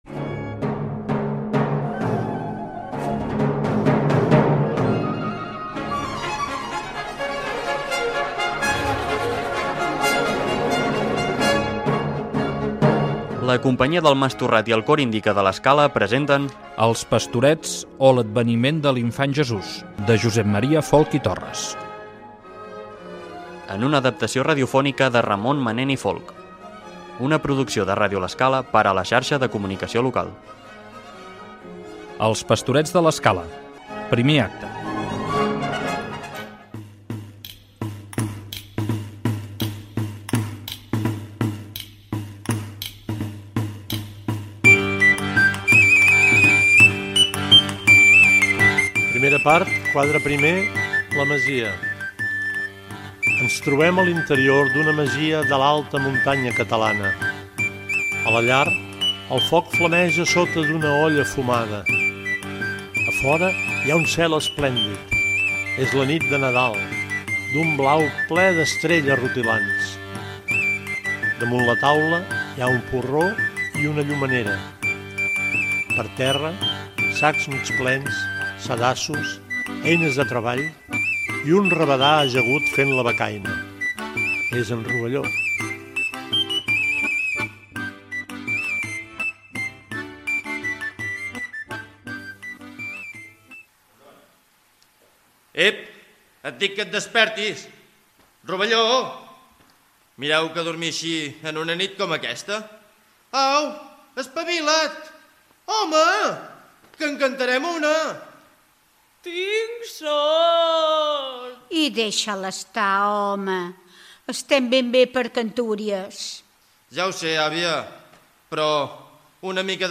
Careta del programa i inici del quadre primer.
Ficció
Versió radiofònica enregistrada a la Sala Polivalent de l'Escala durant al novembre de 2020. La música d'aquests 'Pastorets' és original i es va fer expressament per a aquest programa.